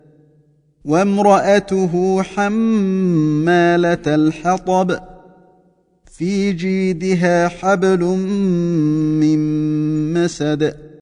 Var och en av dem hålls under en längre tid, lika med varandra med en ghunnah (nasalisering) som åtföljer förlängningen.